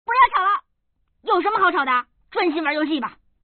Index of /mahjong_paohuzi_Common_test1/update/1577/res/sfx_pdk/woman/